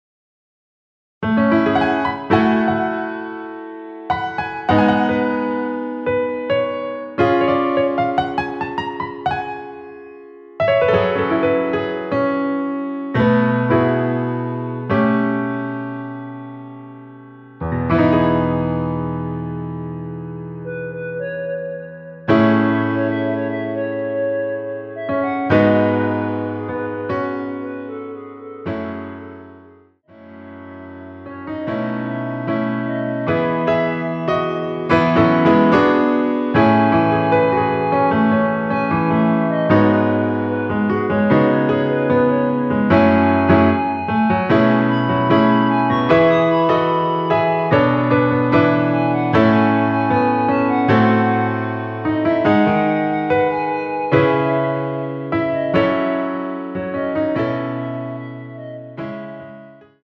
멜로디 MR입니다.
원키에서(+4)올린 멜로디 포함된 MR 입니다.(미리듣기 참조)
노래방에서 노래를 부르실때 노래 부분에 가이드 멜로디가 따라 나와서
앞부분30초, 뒷부분30초씩 편집해서 올려 드리고 있습니다.